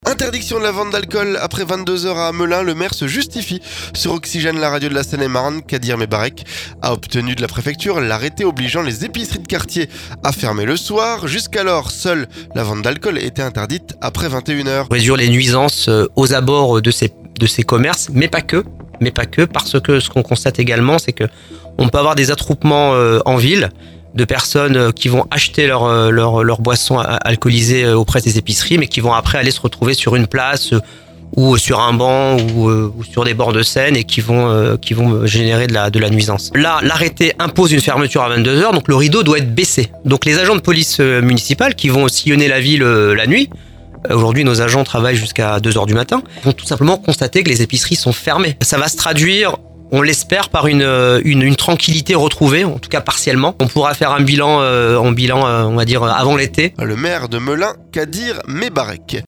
Interdiction de la vente d'alcool après 22h à Melun : le maire se justifie sur Oxygène, la radio de la Seine-et-Marne. Kadir Mebarek a obtenu de la préfecture l'arrêté obligeant les épiceries de quartier à fermer le soir.